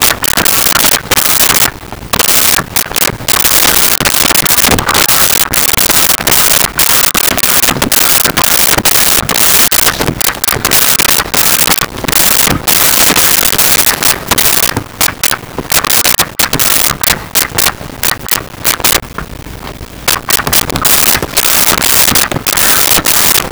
Ducks Quacking
Ducks Quacking.wav